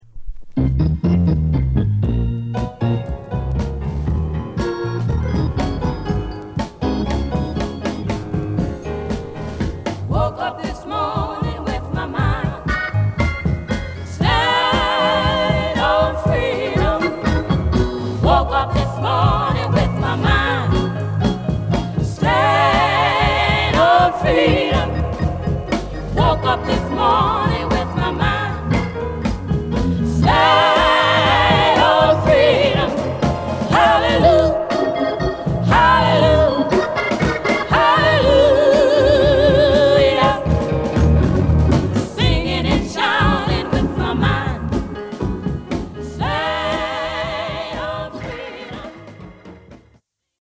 ダーティーで太いベースから始まるファンキーチューンB4